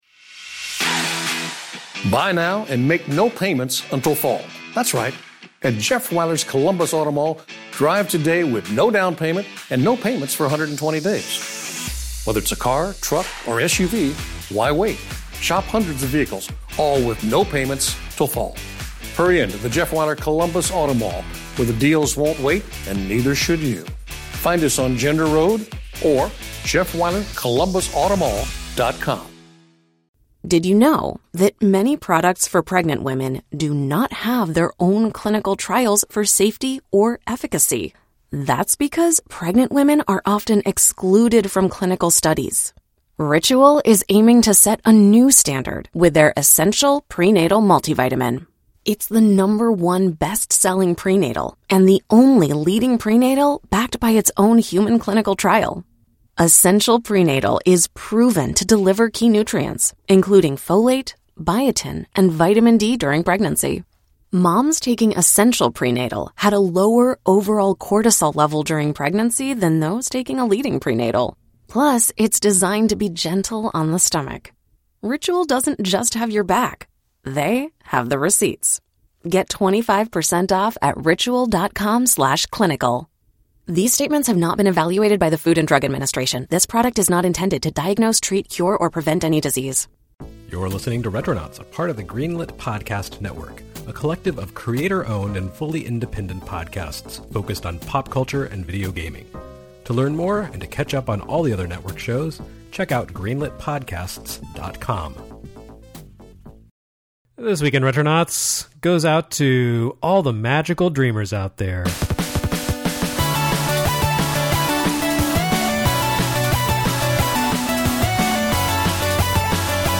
(No weird accents or speech quirks… we pwomise.)